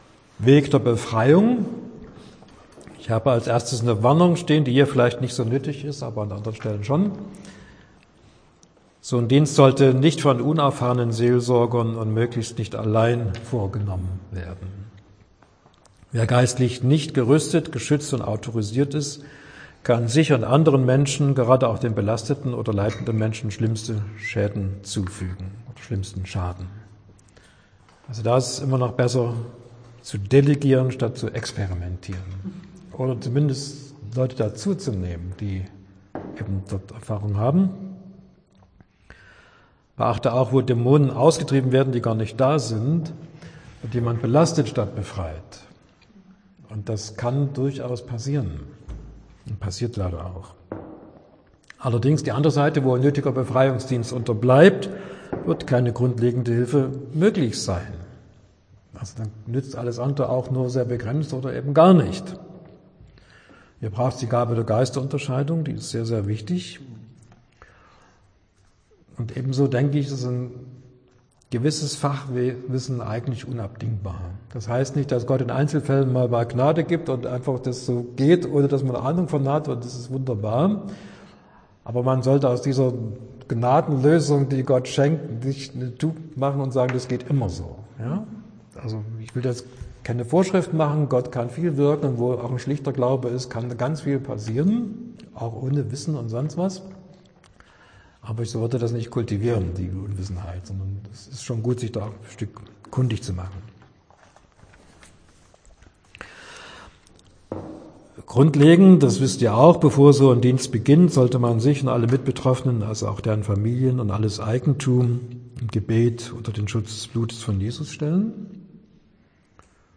September 2025 Heruntergeladen 1 Mal Kategorie Audiodateien Vorträge Schlagwörter Heilung , Seelsorge , bindungen , befreiung , lösung , dämonen , Befreiungsdienst Beschreibung: Seelsorgeschulung Dauer dieses 2.